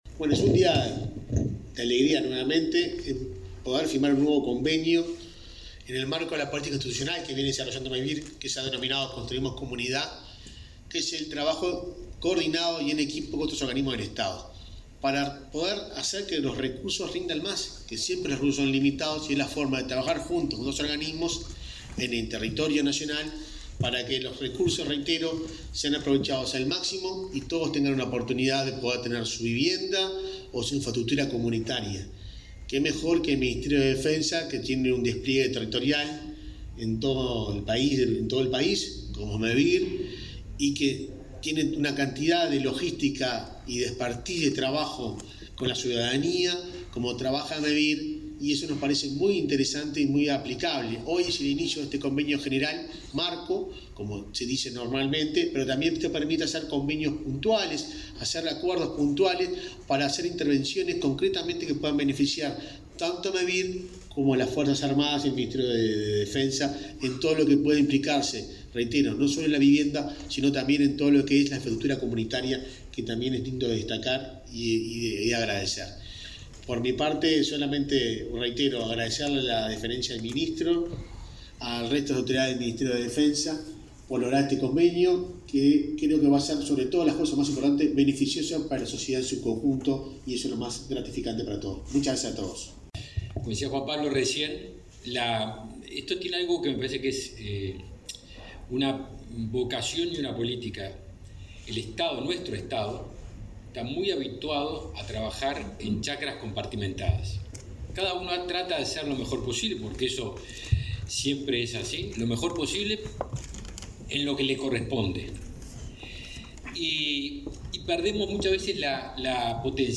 Palabras del presidente de Mevir y del ministro de Defensa Nacional
En ese contexto, el presidente de Mevir, Juan Pablo Delgado, y el ministro de Defensa Nacional, Javier García, destacaron la importancia del mencionado convenio.